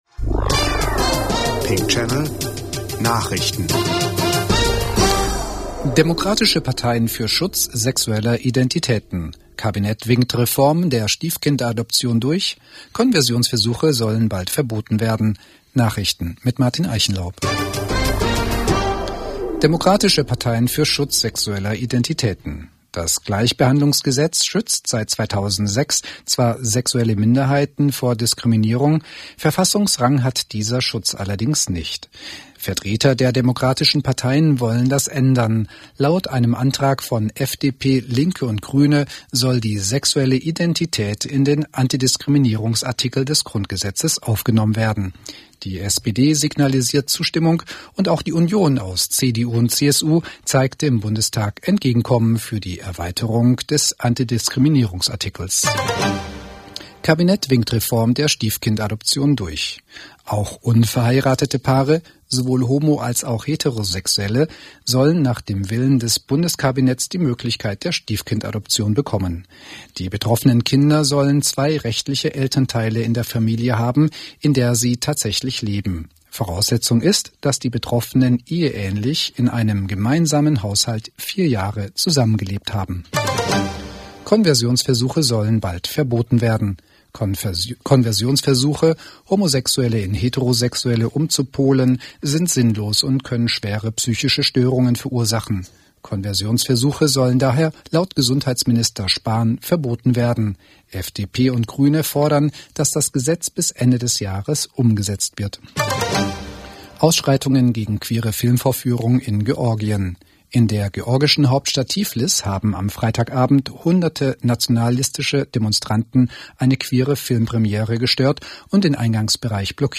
Pink-Channel-Nachrichten 09.11.2019